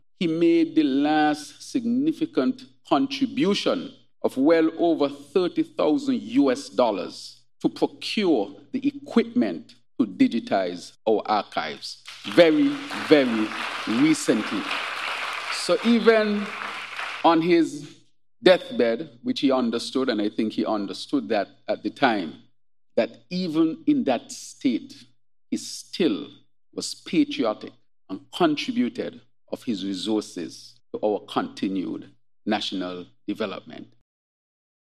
Hon. Dr. Terrance Drew.